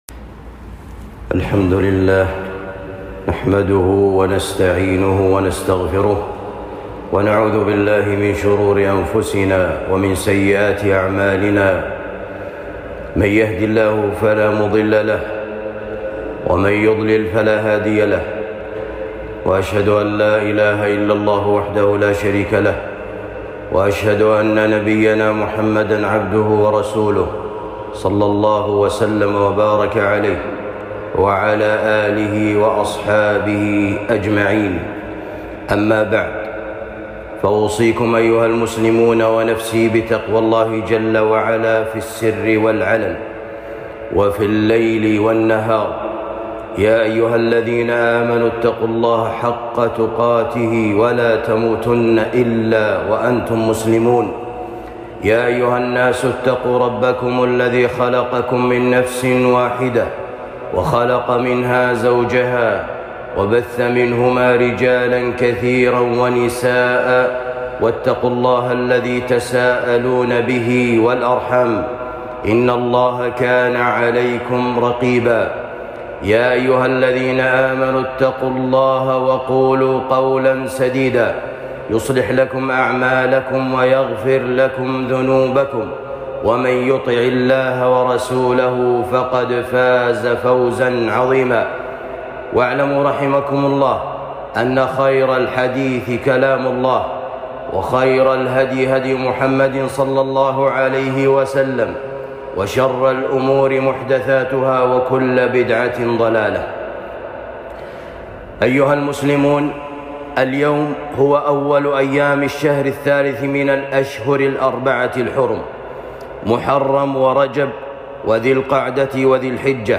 خطبة جمعة بعنوان فضائل شهر ذي القعدة